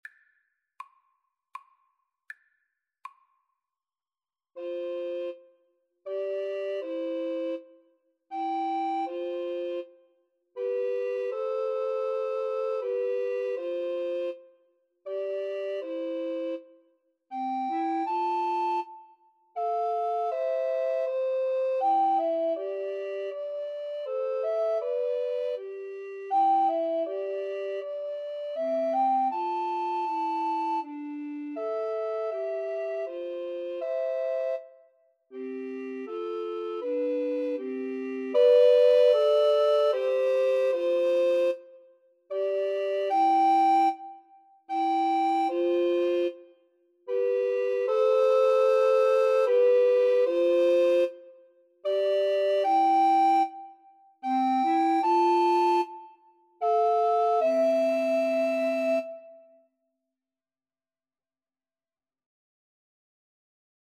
Free Sheet music for Recorder Trio
Alto RecorderTenor Recorder 1Tenor Recorder 2
3/4 (View more 3/4 Music)
Andante
Classical (View more Classical Recorder Trio Music)